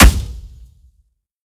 HitsoundUltrakill.ogg